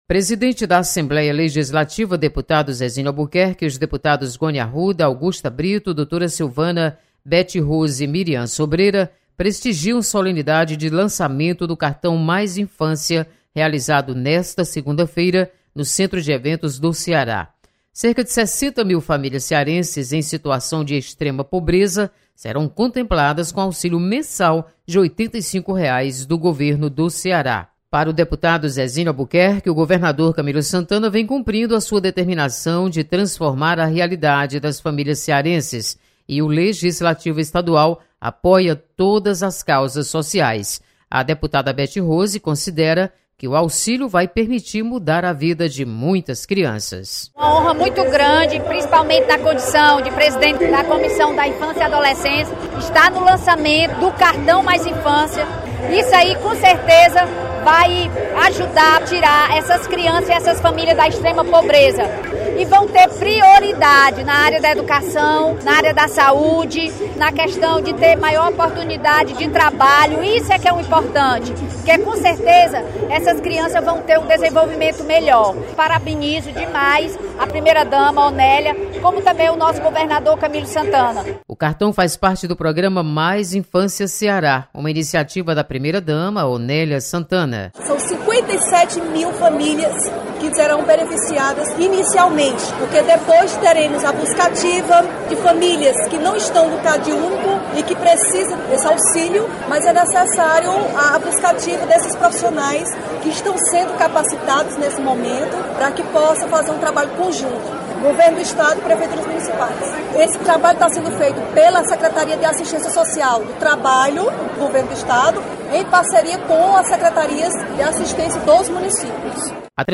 Deputados prestigiam lançamento do Cartão Mais Infância. Repórter